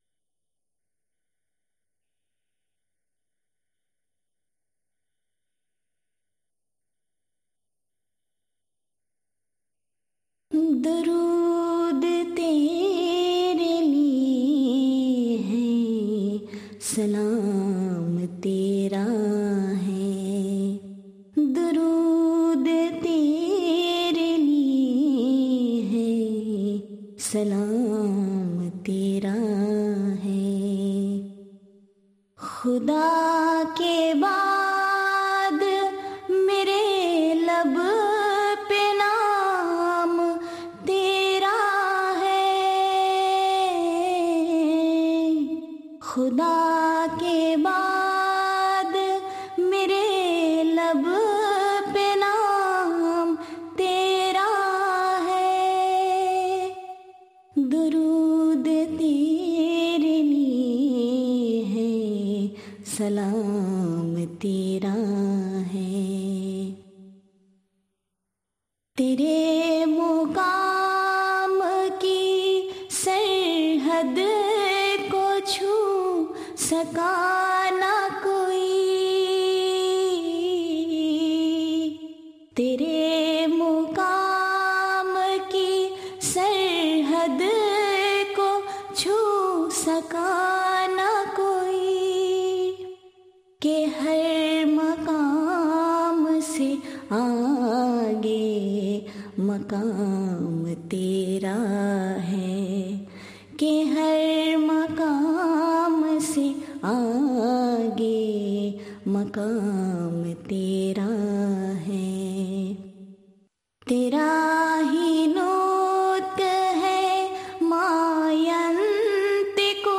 Naat.mp3